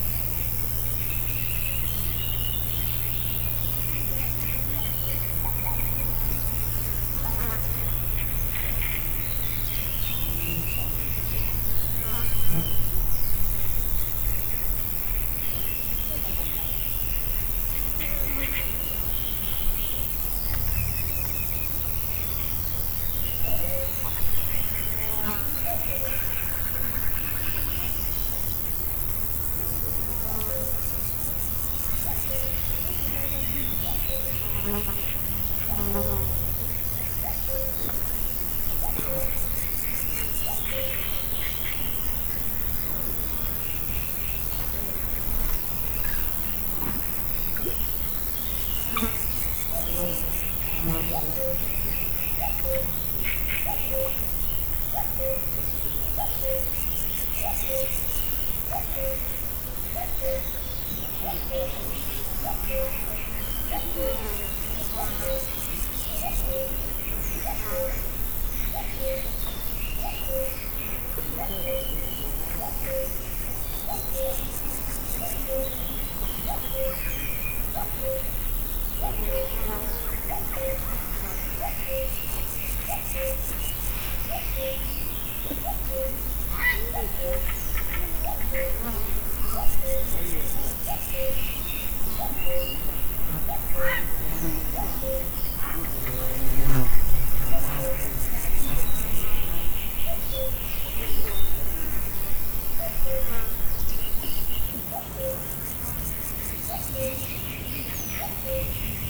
Directory Listing of /_MP3/allathangok/termeszetben/rovarok_premium/
legiparade_tiszababolna01.49.wav